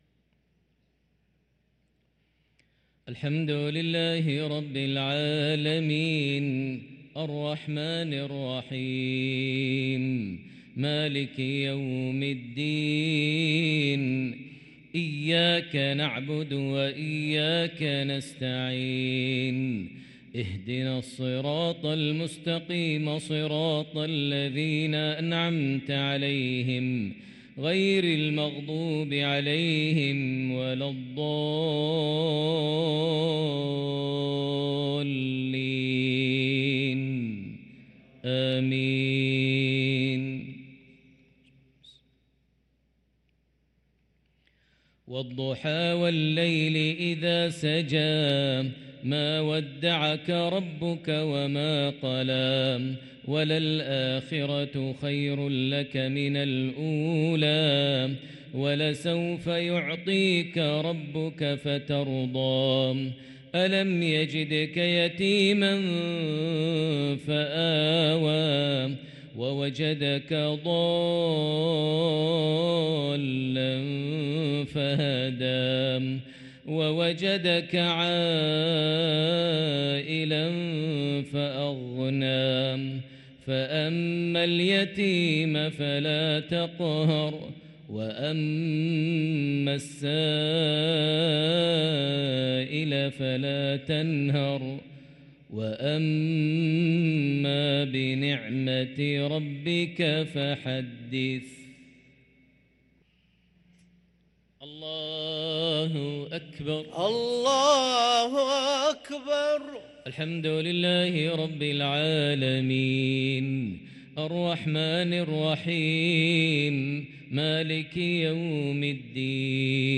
صلاة المغرب للقارئ ماهر المعيقلي 4 ربيع الآخر 1445 هـ
تِلَاوَات الْحَرَمَيْن .